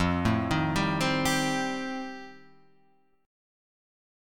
E#79 chord